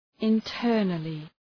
Shkrimi fonetik {ın’tɜ:rnəlı}
internally.mp3